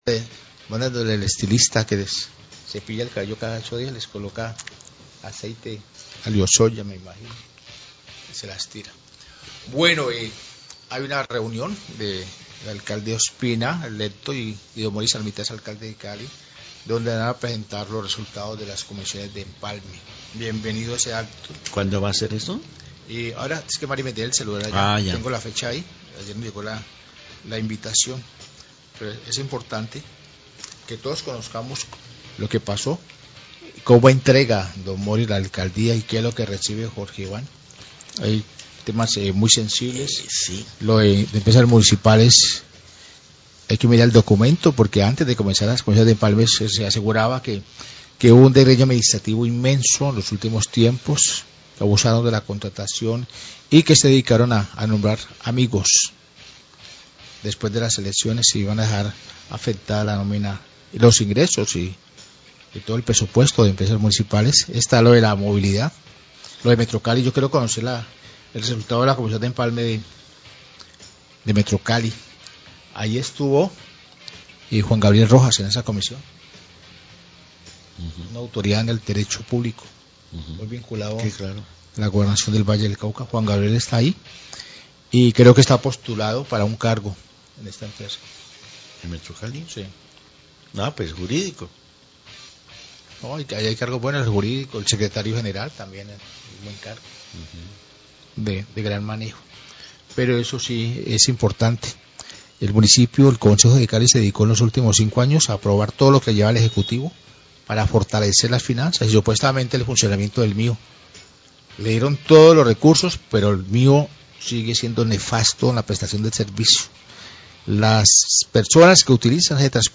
Radio
NOTICIERO RELÁMPAGO